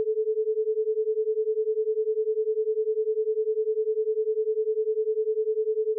The sound below is a stereo file with the 430Hz tone presented in the left ear and the 440Hz tone presented in the right ear.
Example 4: 10Hz Binaural Beat
Two tones presented binaurally (430Hz on the left, 440Hz on the right)
Most people hear the same beating pattern as in the previous example, though often the sound also seems to move left and right at the same time.